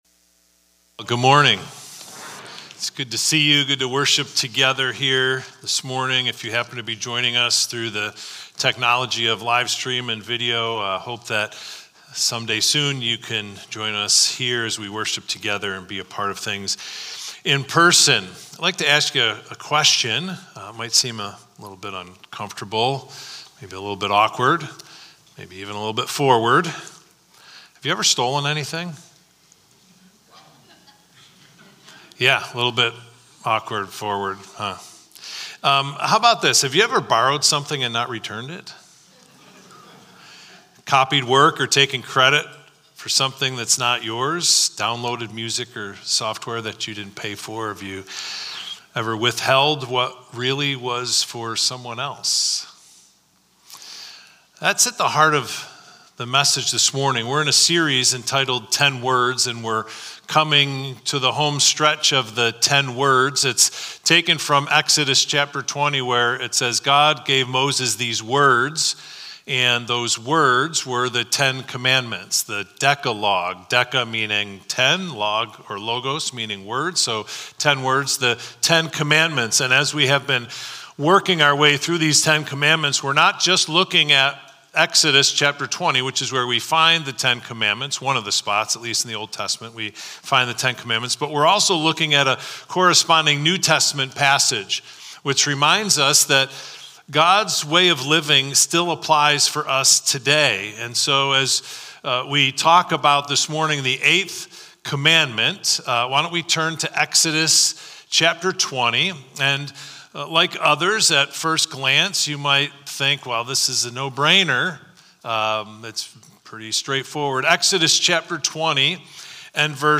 Victor Community Church Sunday Messages / 10 Words: Are You Taking What Belongs To Someone Else?